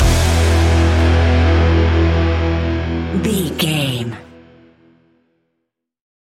Ionian/Major
hard rock
heavy metal
horror rock
instrumentals